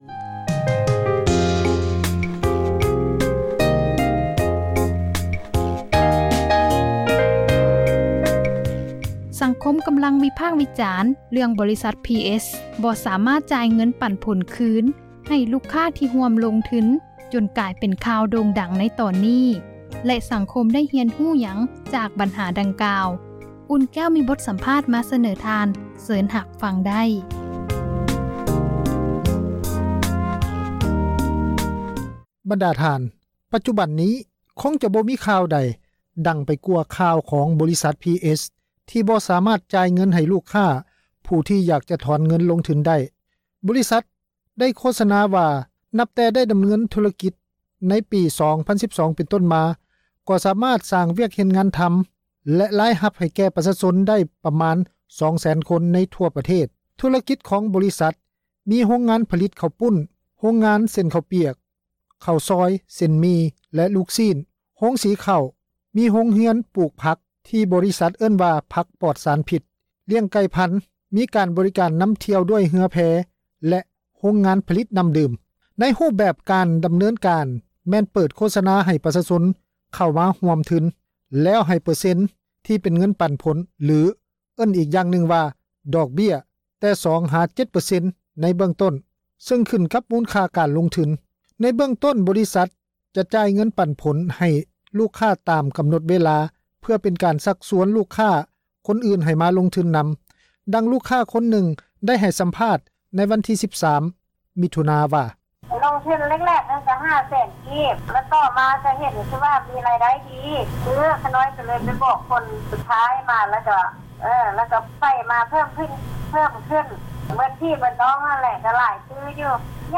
ມີບົດສຳພາດ ມາ ສະເໜີ ທ່ານ.